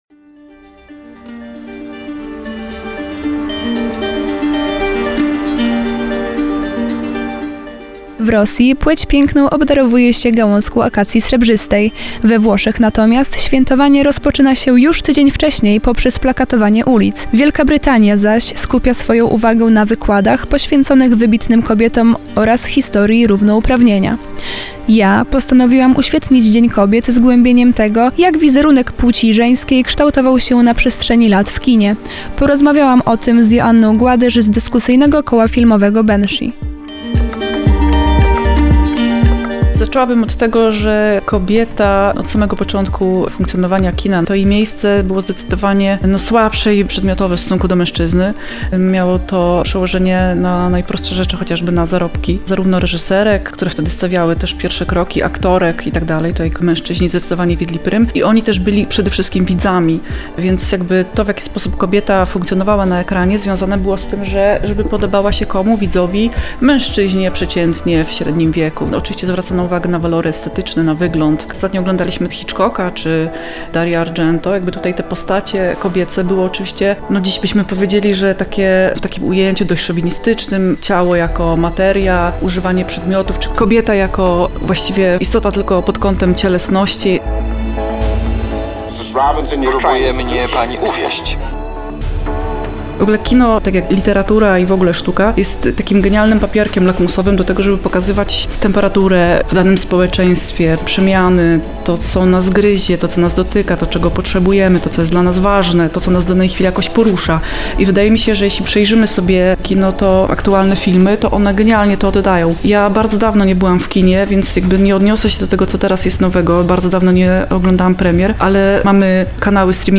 Usłyszymy, czego mogą oczekiwać widzki od dzisiejszego kina, a także, jaka była historia feminizmu. A to wszystko wśród cytatów z klasycznych dzieł kinematografii.